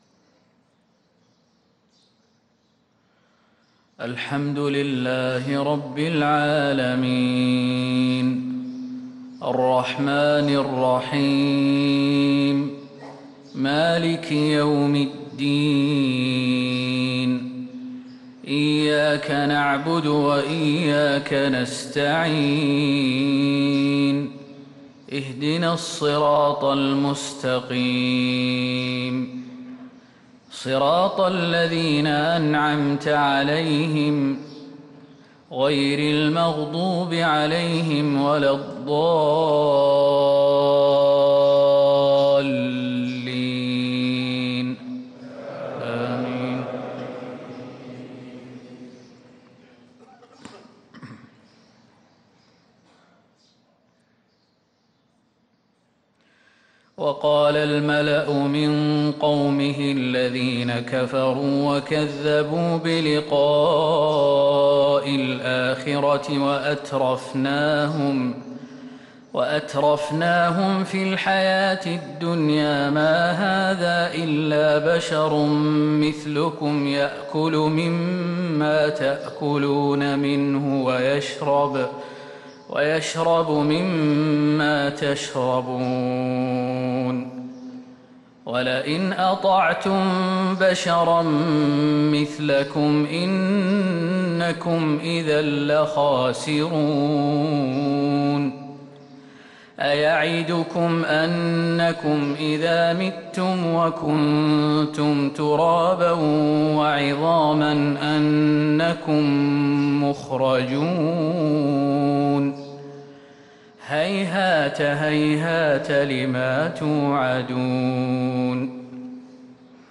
فجر الاثنين 9-2-1444هـ من سورة المؤمنون | Fajr prayer from Surat Al-mu’menoon 5-9-2022 > 1444 🕌 > الفروض - تلاوات الحرمين